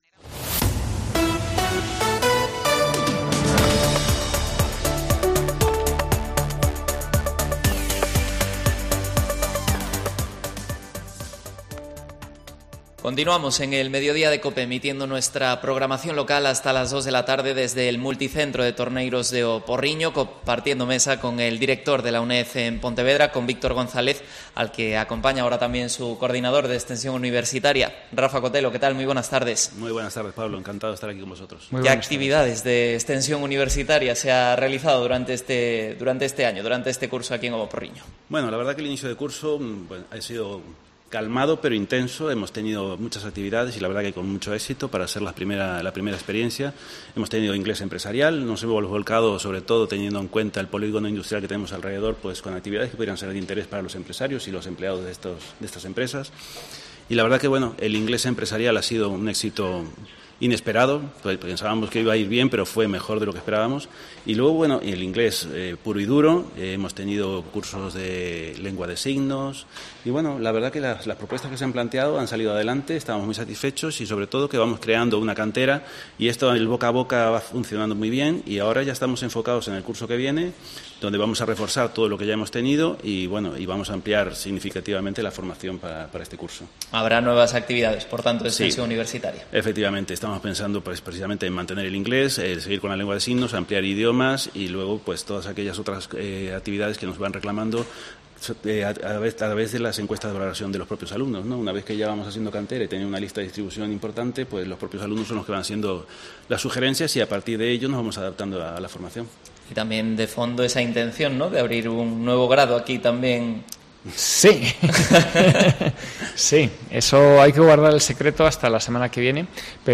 Redacción digital Madrid - Publicado el 06 jul 2023, 14:14 - Actualizado 06 jul 2023, 14:17 1 min lectura Descargar Facebook Twitter Whatsapp Telegram Enviar por email Copiar enlace Programa Especial desde el Aula Universitaria de la UNED Pontevedra en O Porriño.